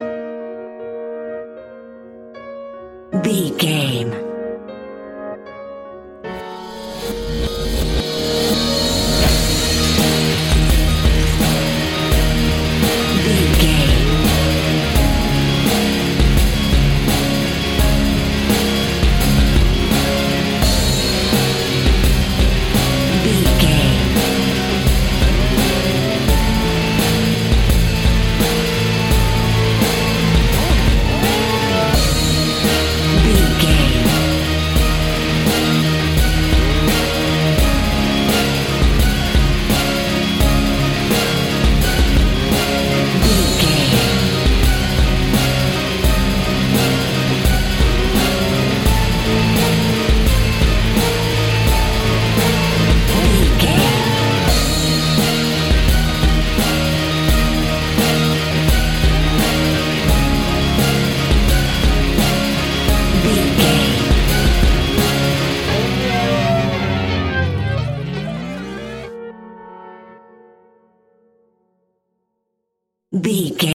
Aeolian/Minor
E♭
Fast
ominous
disturbing
eerie
piano
drums
strings
electric guitar
bass guitar
viola
rock guitar